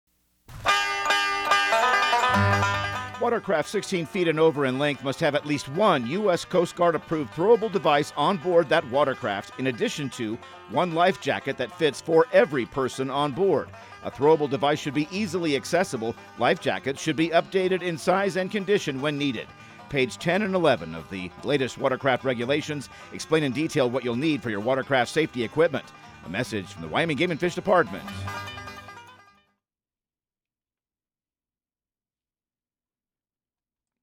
Radio news | Week of June 16
Outdoor Tip/PSA